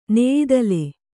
♪ neyidale